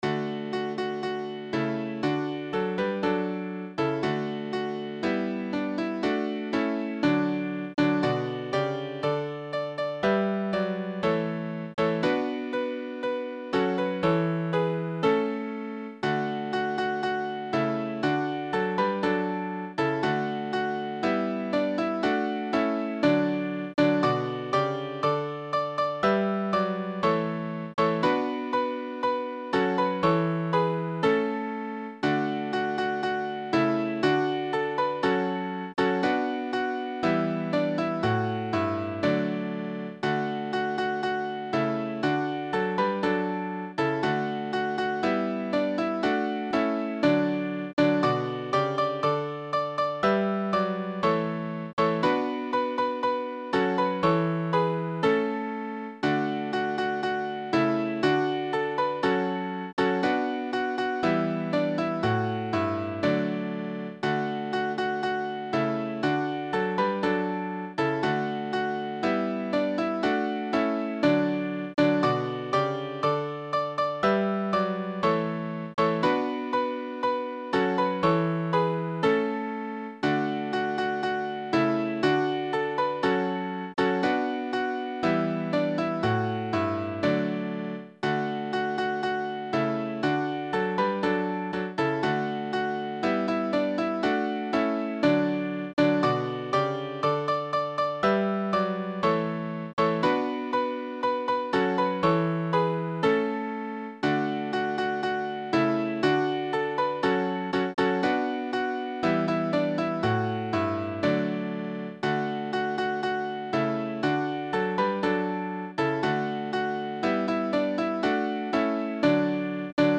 Midi File Transcriptions